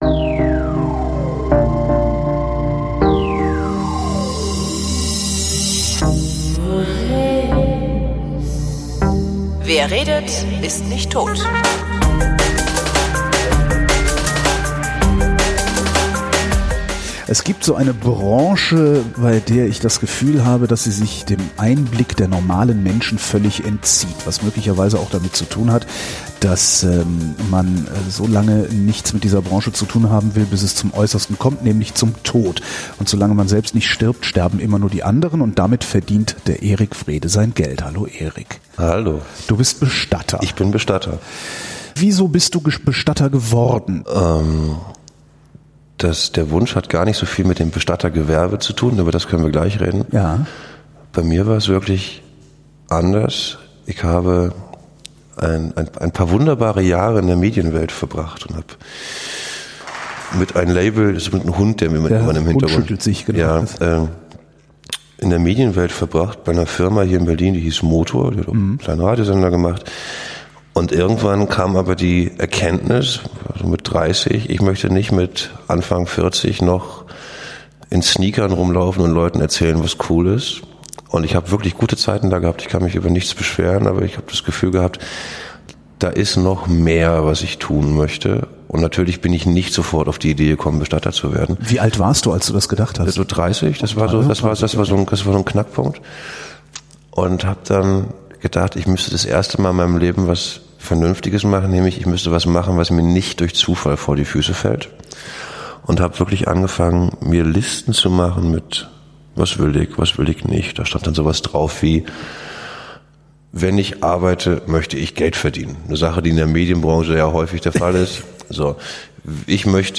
Knapp zwei Stunden haben wir neben einem Sarg gesessen und hauptsächlich über die Dinge geredet, die passieren, nachdem ein Mensch gestorben ist.